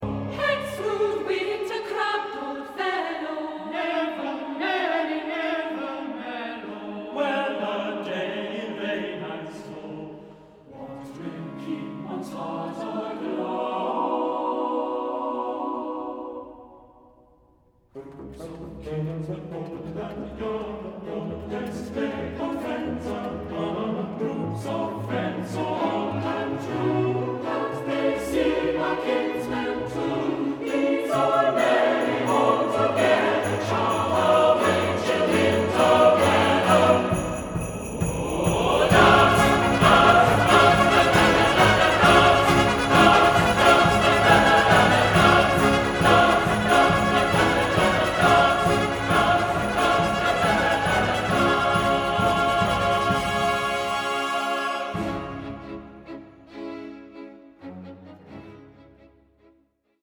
Choir & Baroque Orchestra